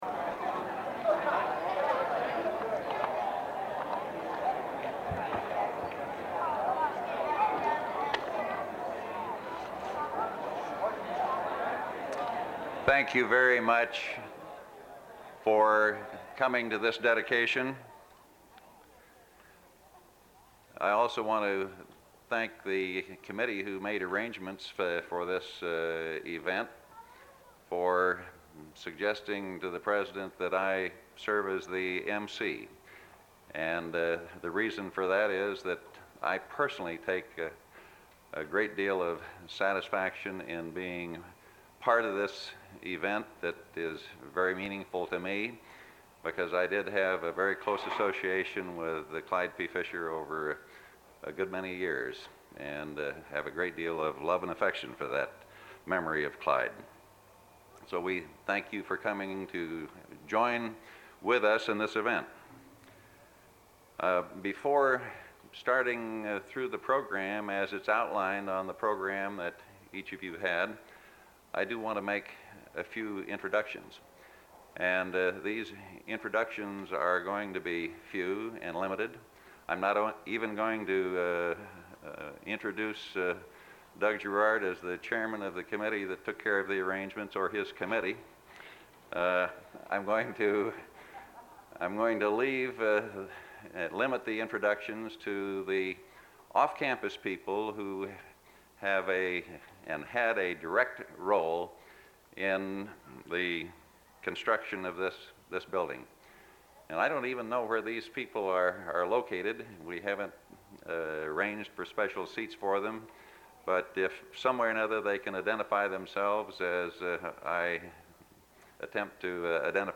[00:00:00] Audience chatter [00:00:15] Beginning of speech
[00:31:20] Cut in tape between sides one and two
[00:37:05] Emcee closes ceremony and directs people to tours
Form of original Audiocassette